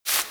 SandStep5.wav